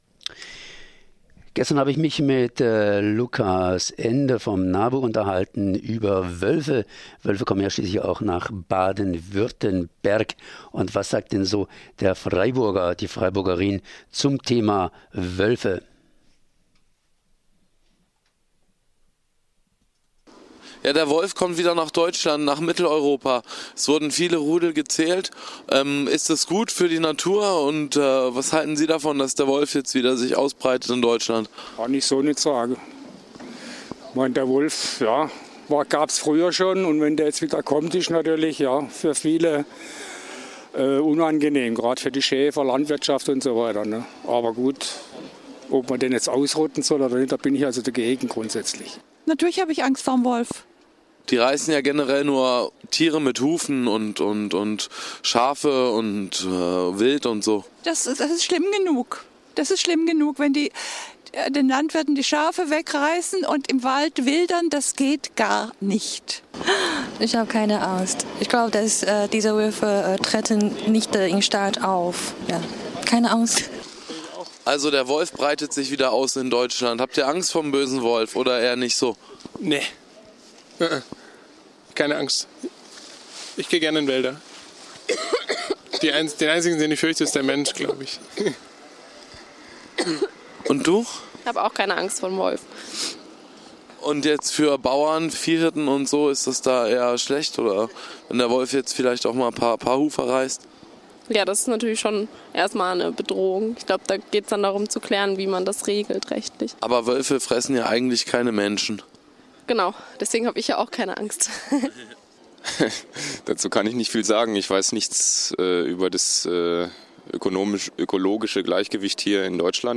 Umfrage unter Freiburgern zum Wolf.